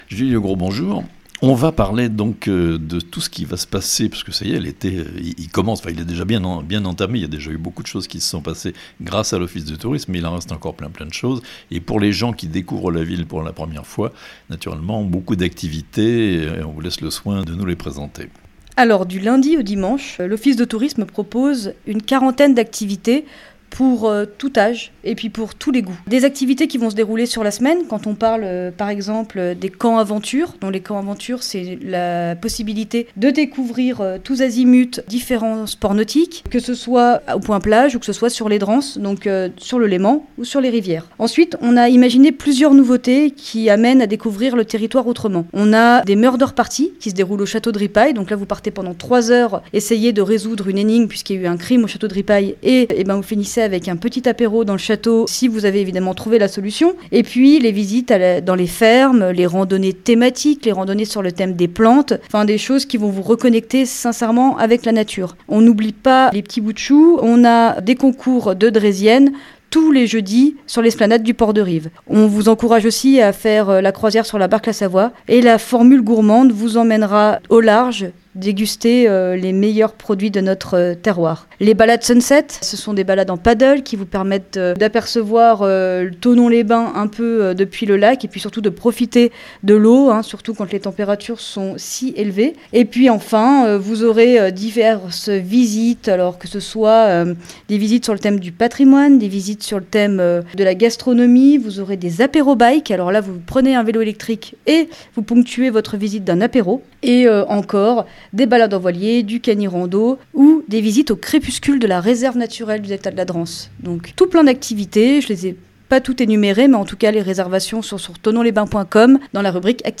Des vacances insolites à Thonon (interview)